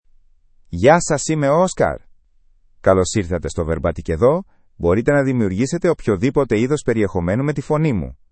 MaleGreek (Greece)
OscarMale Greek AI voice
Voice sample
Listen to Oscar's male Greek voice.
Oscar delivers clear pronunciation with authentic Greece Greek intonation, making your content sound professionally produced.